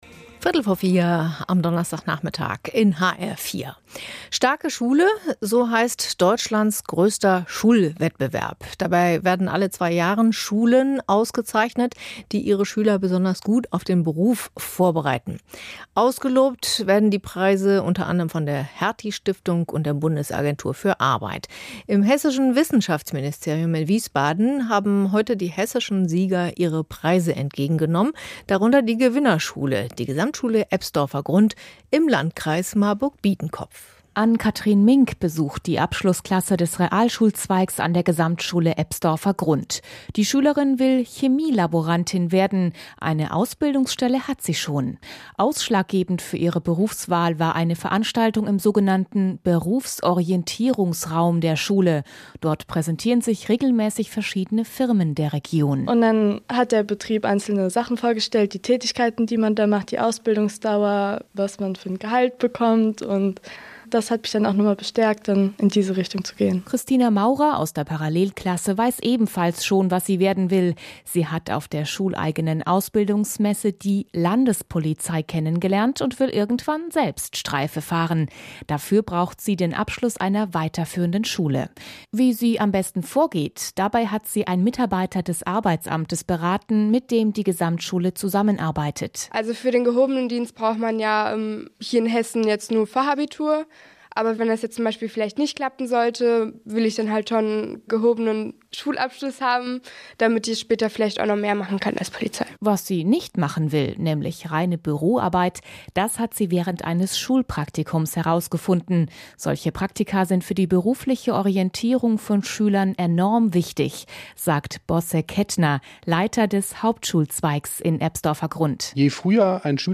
Rundfunk-Beitrag von hr4